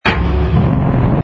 engine_rh_freighter_start.wav